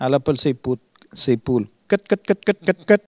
Elle crie pour appeler les poules
Catégorie Locution